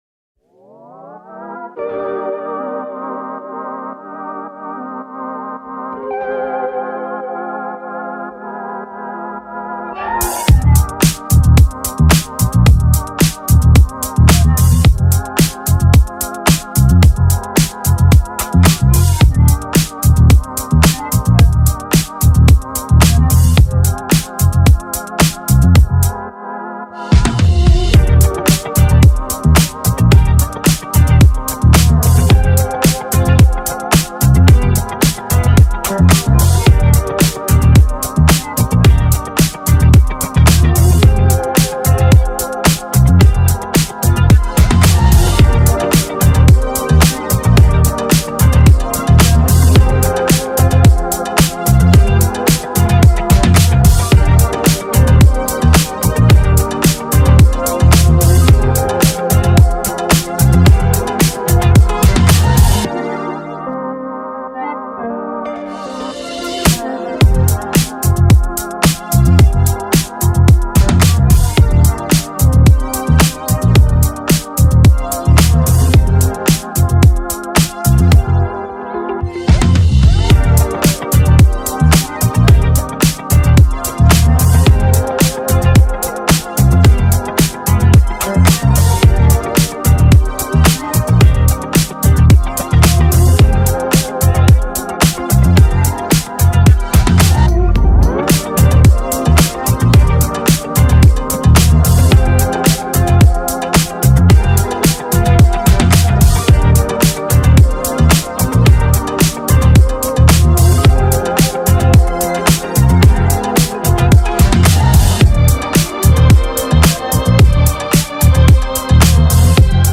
This is the official instrumental
2024 in K-Pop Instrumentals